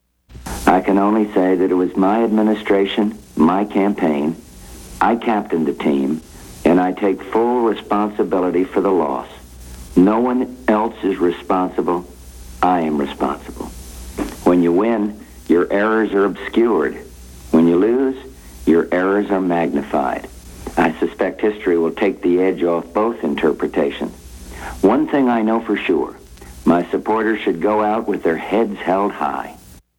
President George Bush, on his weekly radio broadcast, takes full responsibility for his defeat in his bid for re-election